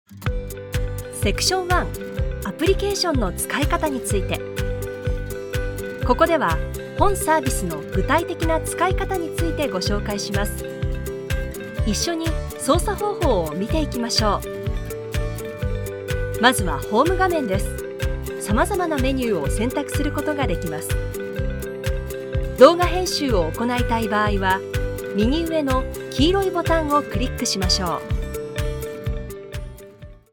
Japanese voice over, Japanese voice, Japanese commercial, corporate, business, documentary, e-learning, product introductions
Sprechprobe: eLearning (Muttersprache):
E-learning_3.mp3